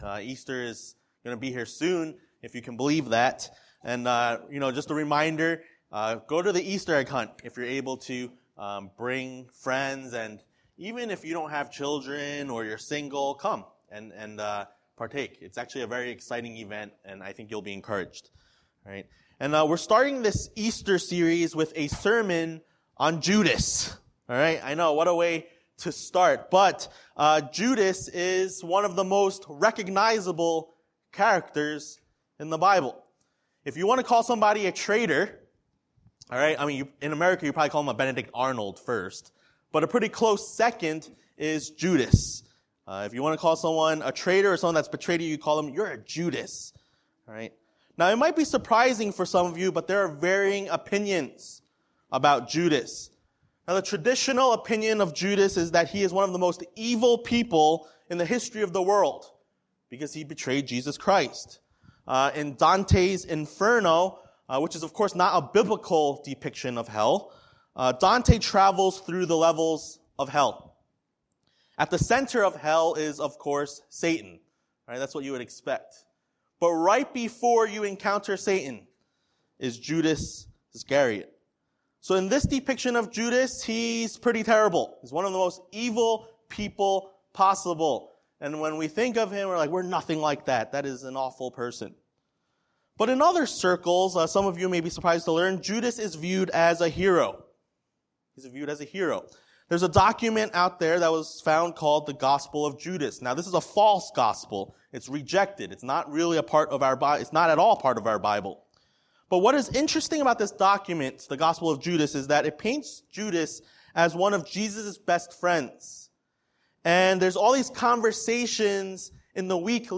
A message from the series "James: Faith In Action."